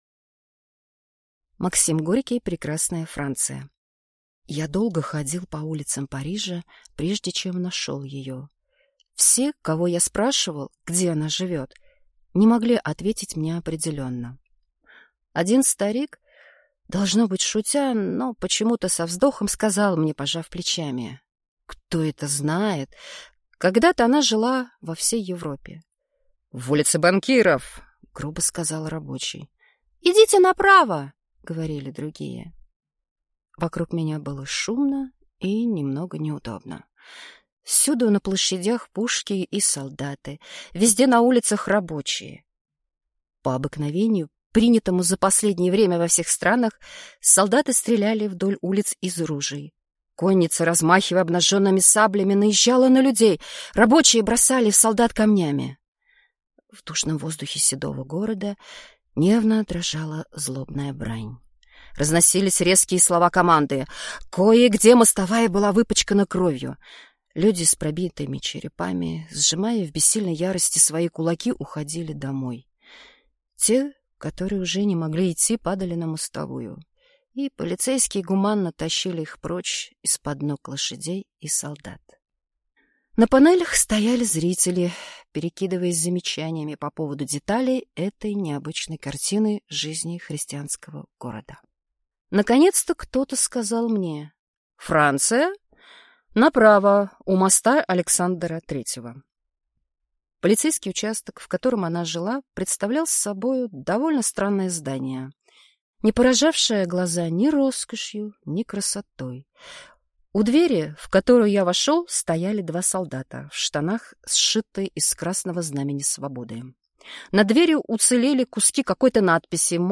Аудиокнига Прекрасная Франция | Библиотека аудиокниг